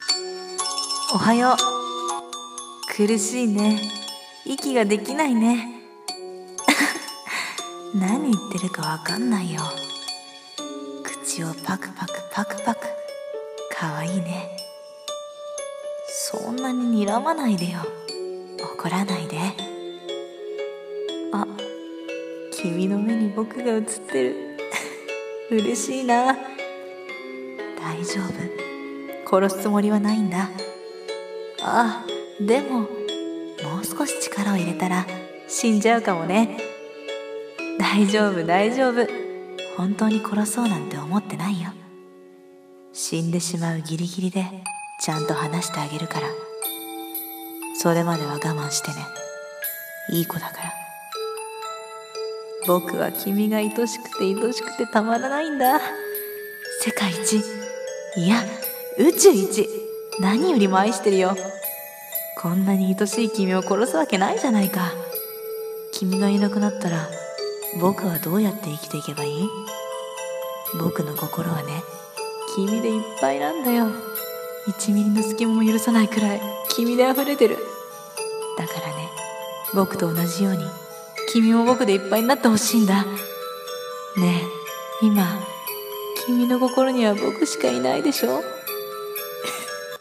声劇「絞首」